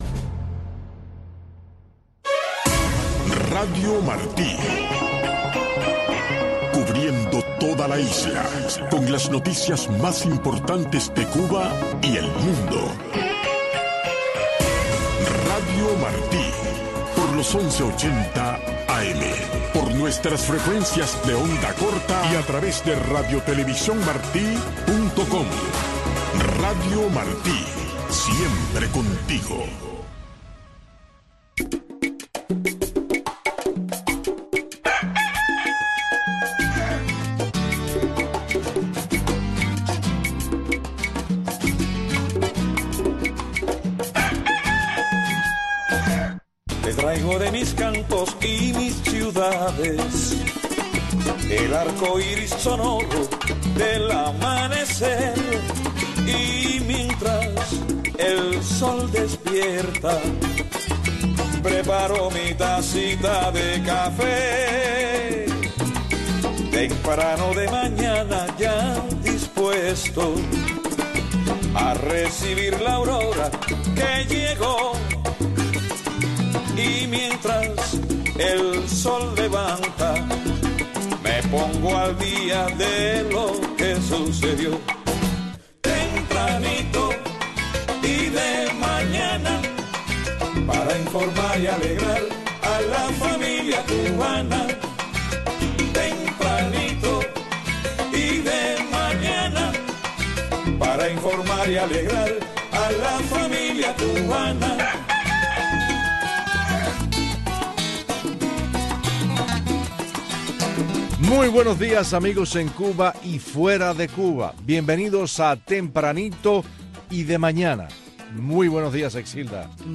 Disfrute el primer café de la mañana escuchando a Tempranito, una atinada combinación de noticiero y magazine, con los últimos acontecimientos que se producen en Cuba y el resto del mundo.